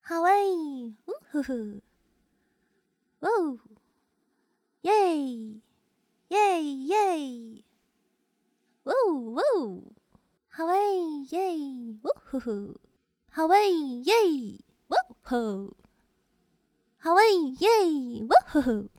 欢呼1.wav
欢呼1.wav 0:00.00 0:18.99 欢呼1.wav WAV · 1.6 MB · 單聲道 (1ch) 下载文件 本站所有音效均采用 CC0 授权 ，可免费用于商业与个人项目，无需署名。
人声采集素材/人物休闲/欢呼1.wav